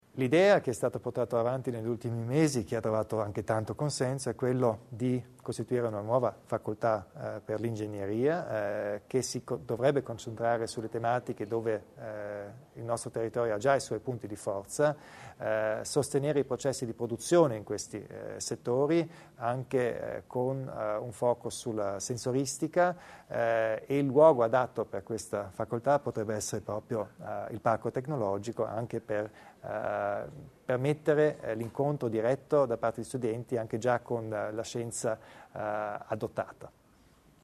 Il Presidente Kompatscher illustra il progetto della facoltà d ingegneria
Lo ha detto oggi (29 dicembre) durante l'incontro di fine anno con i media locali il presidente della Provincia di Bolzano, Arno Kompatscher.